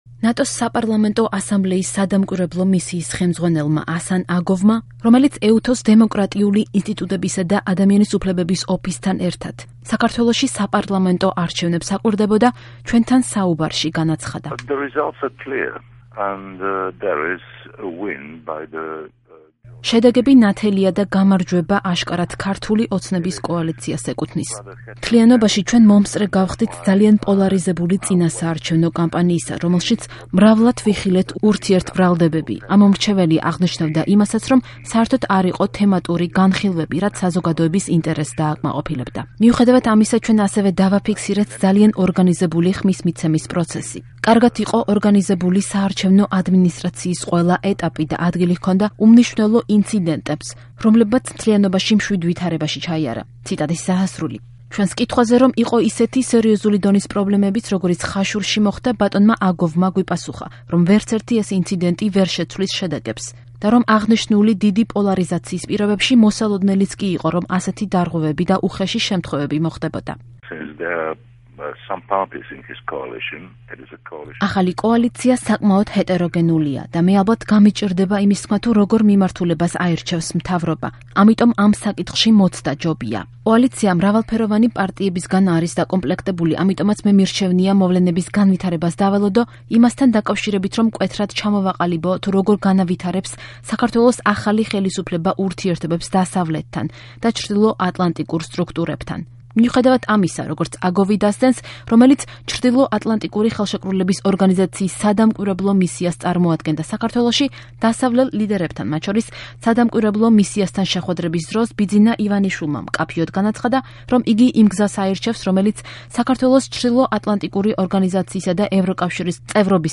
კომენტარს აკეთებს ნატოს საპარლამენტო დელეგაციის სადამკვირვებლო მისიის ხელმძღვანელი ასან აგოვი
ინტერვიუ